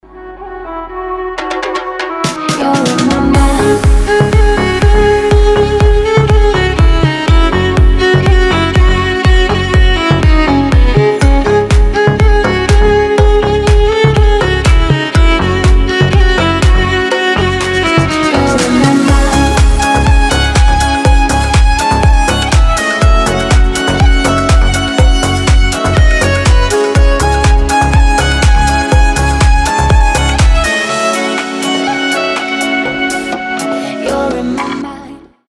• Качество: 320, Stereo
deep house
восточные мотивы
женский голос
dance
скрипка